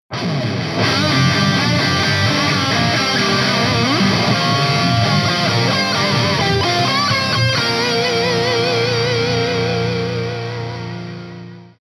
Na oboch mikrofonoch je HiPass filter nastaveny na 100Hz.
Toto su ukazky len gitar:
dynamika